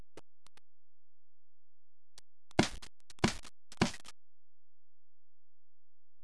スライド固定式のガスガンですから、サイレンサーの効果が実感できます。
サイレンサー有り発射音